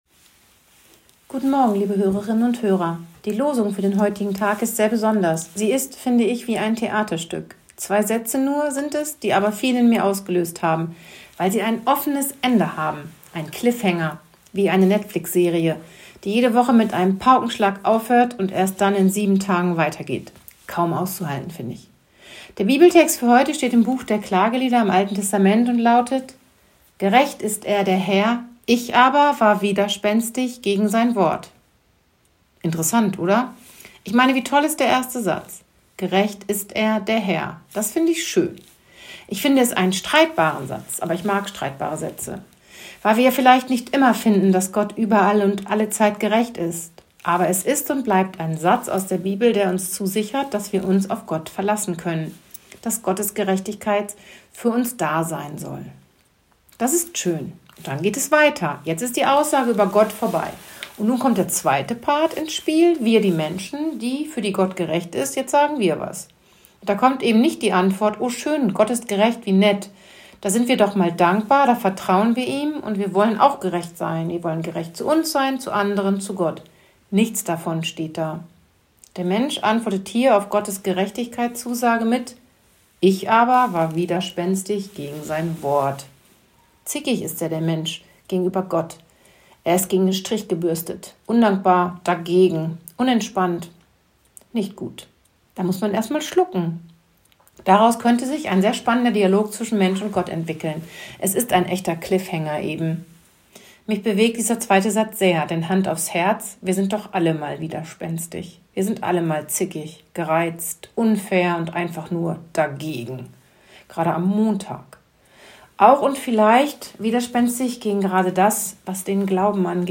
Radioandacht vom 29. April